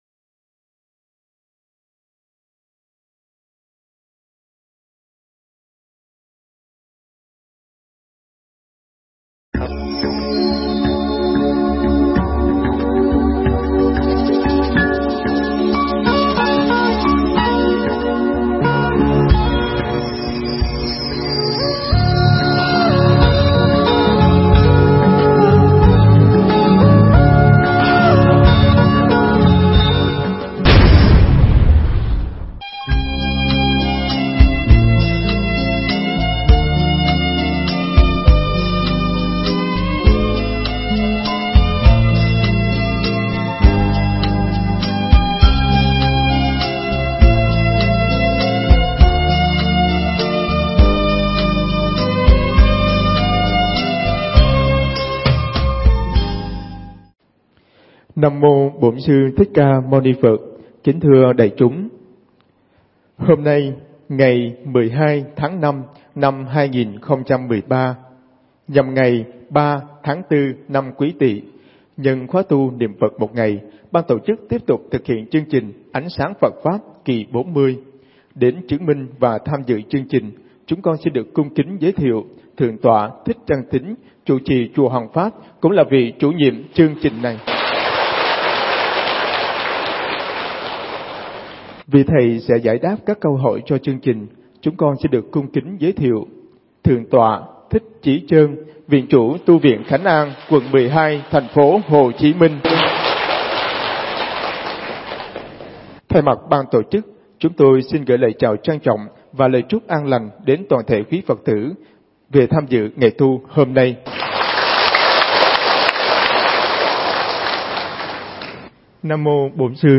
Nghe Mp3 thuyết pháp Ánh Sáng Phật Pháp Kỳ 40